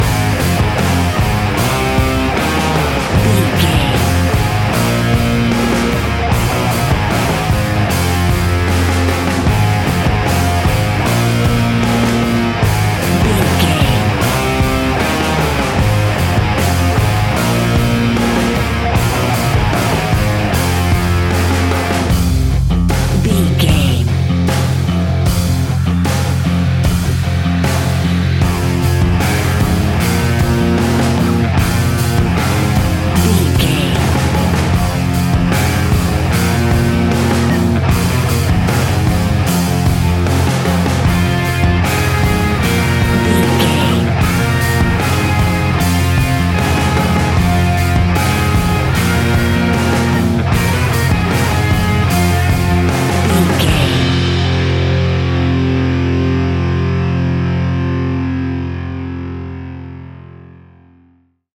Ionian/Major
F♯
hard rock
distortion
instrumentals